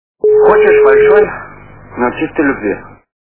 » Звуки » Из фильмов и телепередач » Хочешь большой, - но чистой любви
При прослушивании Хочешь большой, - но чистой любви качество понижено и присутствуют гудки.